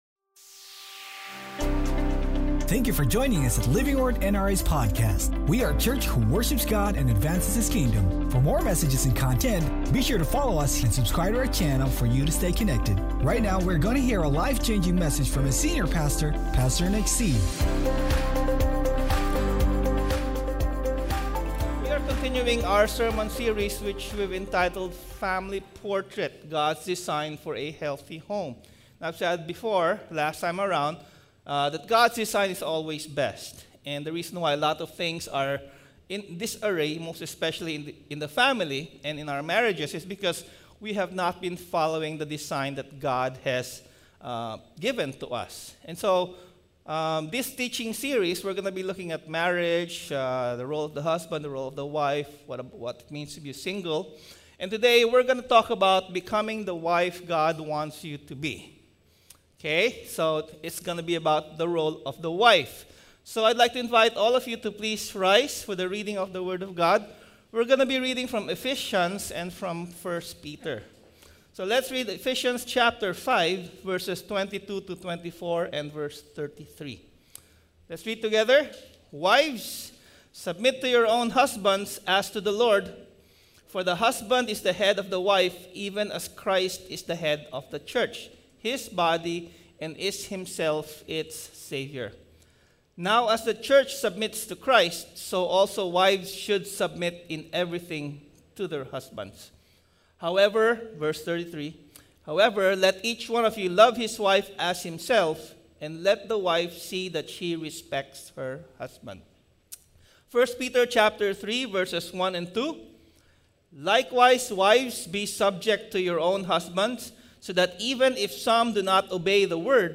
Sermon Title: BECOMING THE WIFE THAT GOD WANTS YOU TO BE